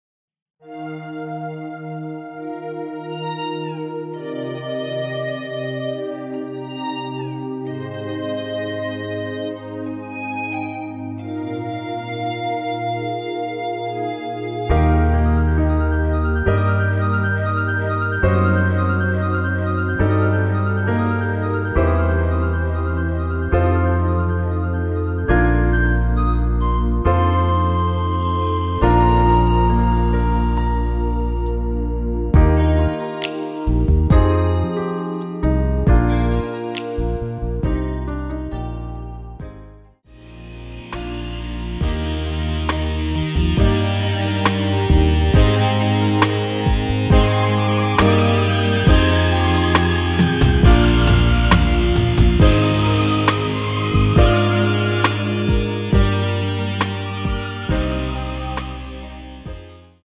원키에서(+3)올린 MR입니다.
Eb
앞부분30초, 뒷부분30초씩 편집해서 올려 드리고 있습니다.
중간에 음이 끈어지고 다시 나오는 이유는